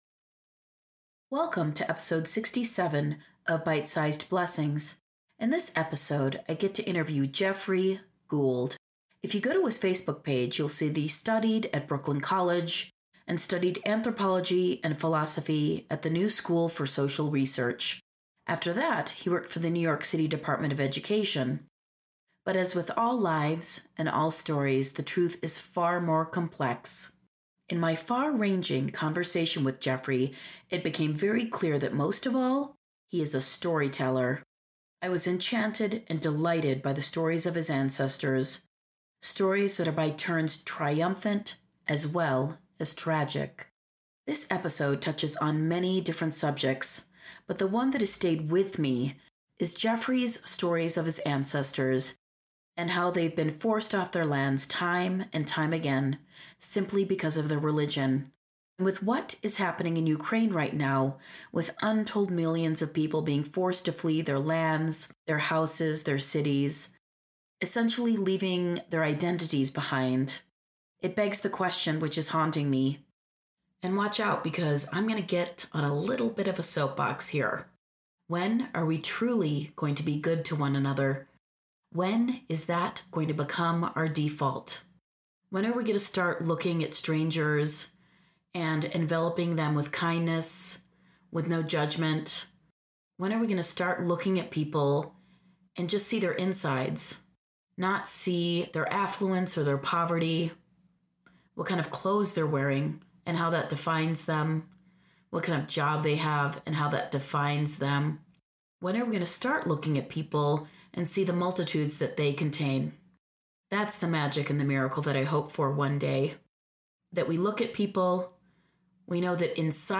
Byte Sized Blessings / The Interview